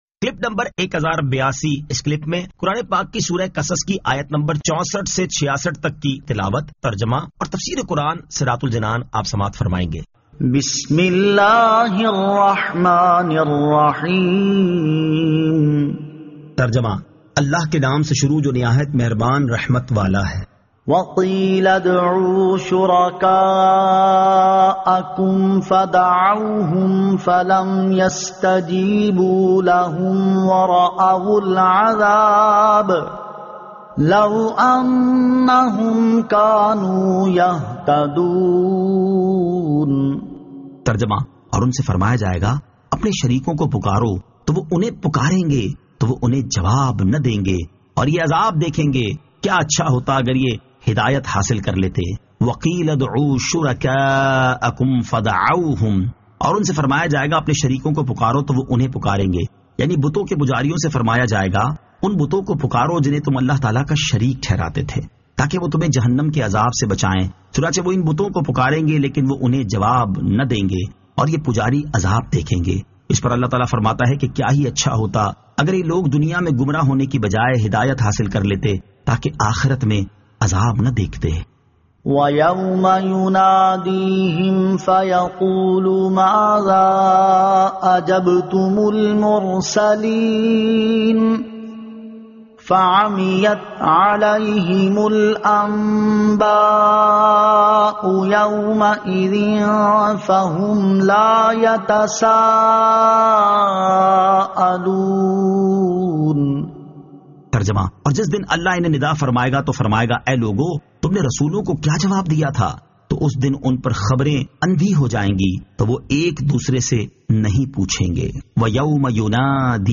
Surah Al-Qasas 64 To 66 Tilawat , Tarjama , Tafseer